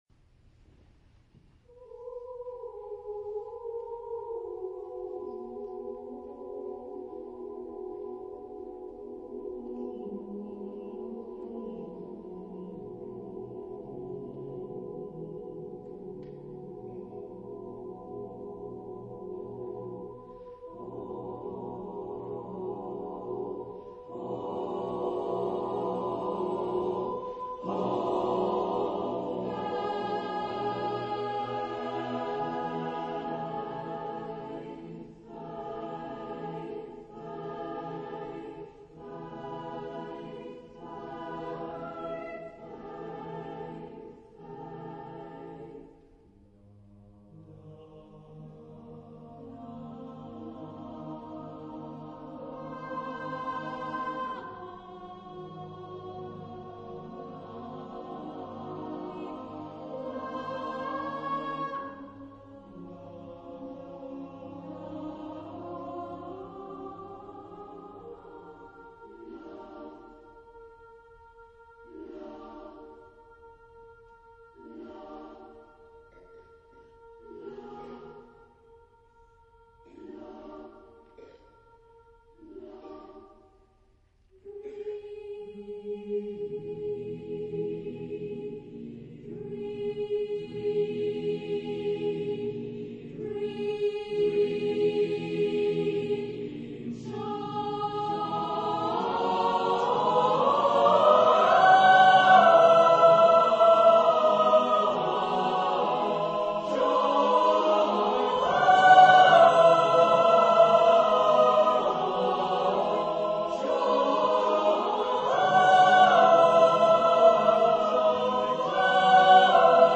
Género/Estilo/Forma: Obra coral ; ciclo ; Profano
Tipo de formación coral: SSAATTBB  (8 voces Coro mixto )
Tonalidad : libre